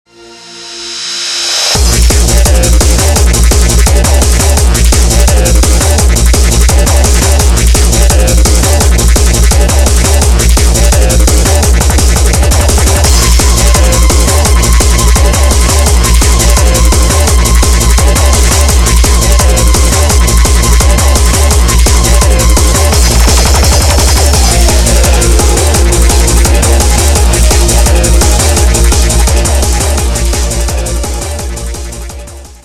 • Качество: 192, Stereo
Хард Басс